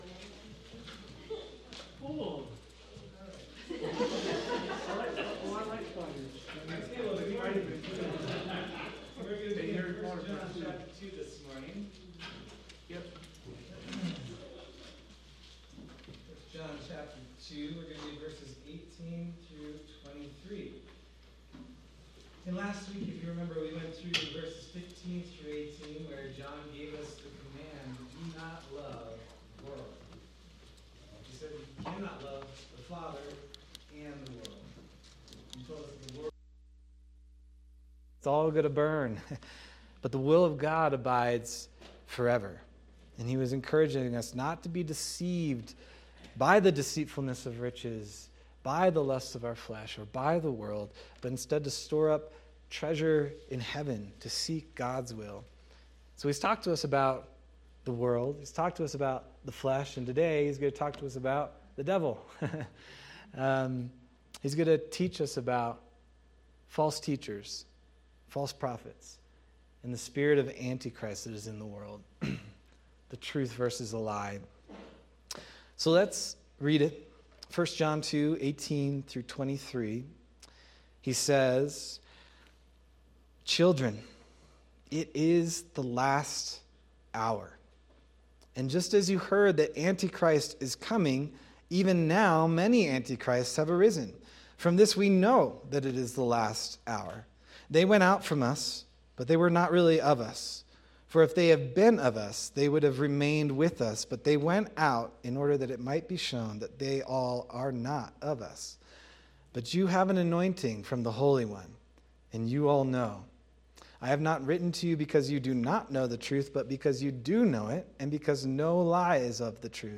February 22nd, 2026 Sermon – Calvary Chapel Nederland